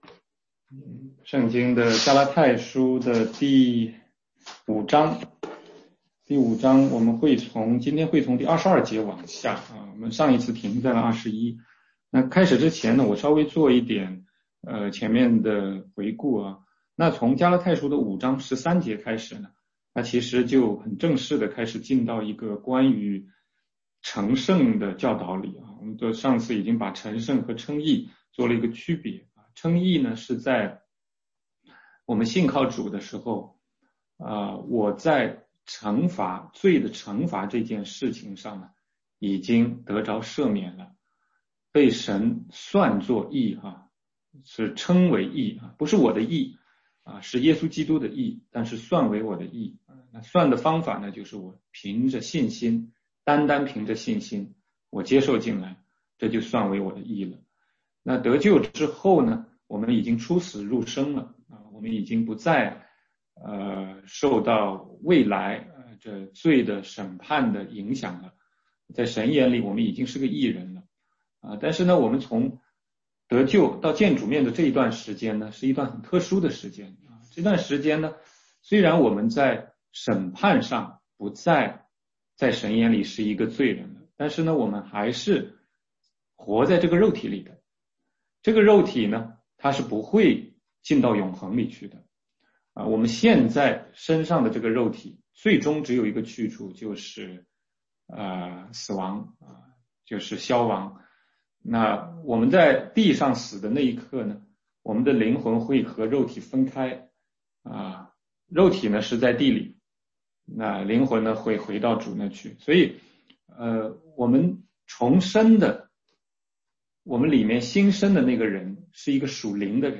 16街讲道录音 - 加拉太书5章22节-6章1节：既靠圣灵得生，就当靠圣灵行事